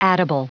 Prononciation du mot addible en anglais (fichier audio)
Prononciation du mot : addible